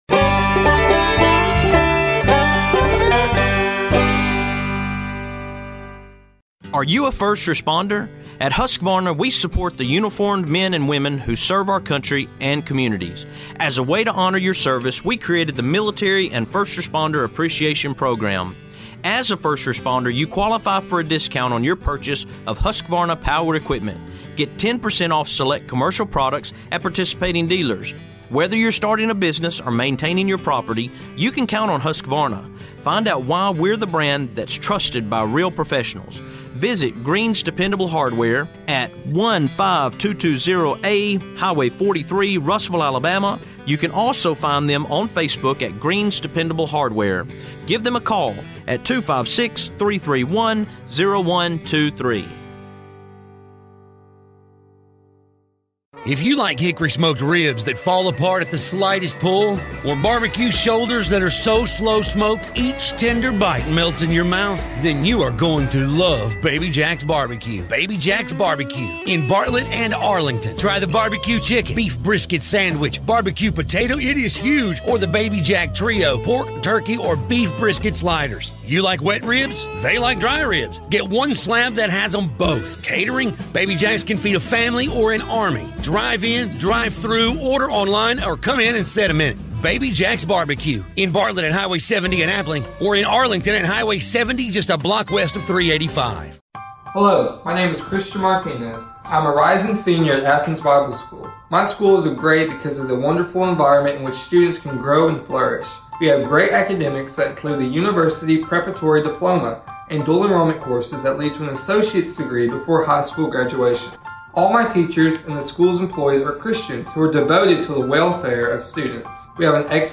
(Full Interview)